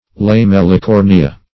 Lamellicornia \La*mel`li*cor"ni*a\, n. pl. [NL. See